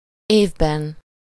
Ääntäminen
Ääntäminen Tuntematon aksentti: IPA: /ˈeːvbɛn/ Haettu sana löytyi näillä lähdekielillä: unkari Käännöksiä ei löytynyt valitulle kohdekielelle.